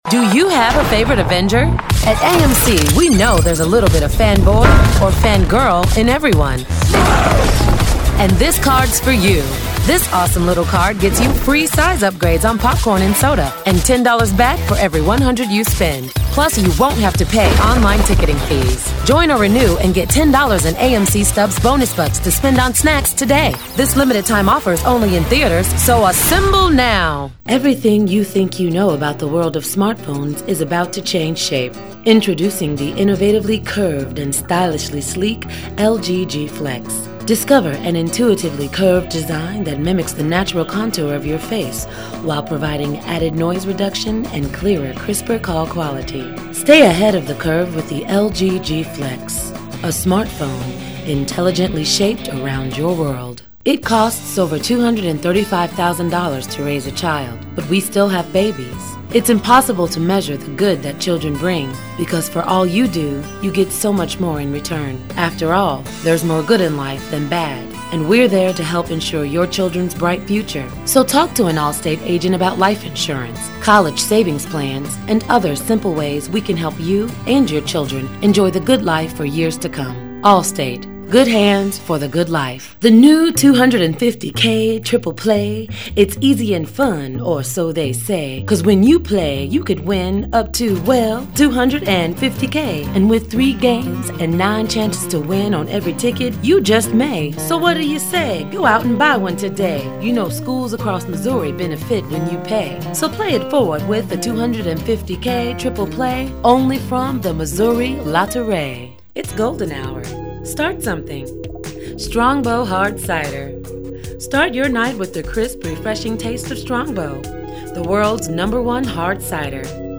Gender: Female
VOICE OVER DEMO